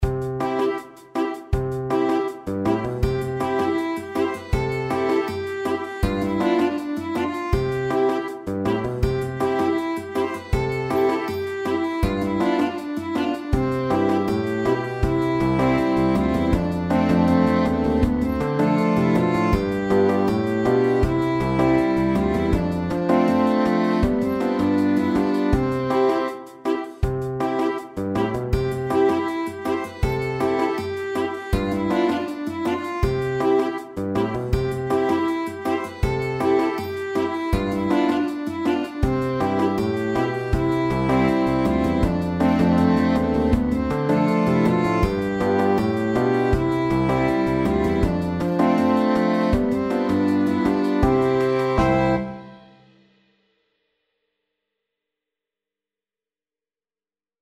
GuitarAlto SaxophoneViolin
Alto SaxophoneTrumpet
Bass GuitarDrum KitKeyboard
4/4 (View more 4/4 Music)
Fast, reggae feel =c.160 =160